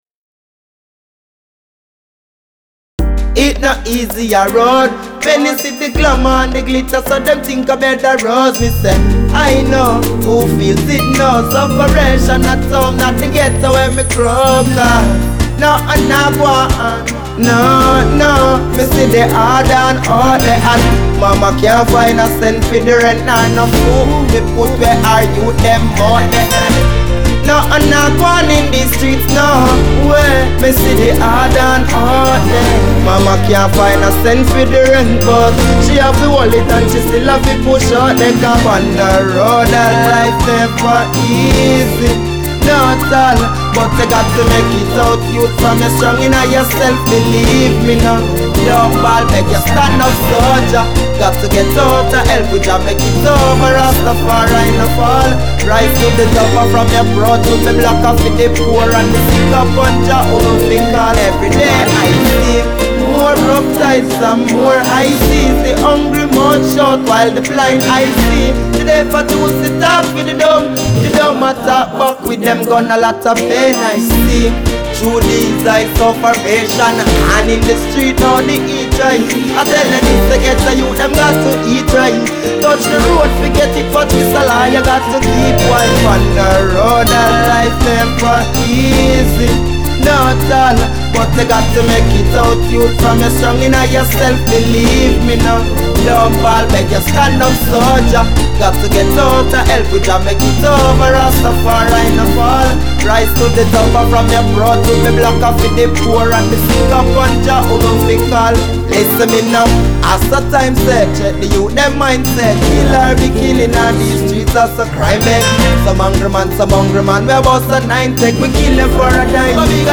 reggae music
cultural roots reggae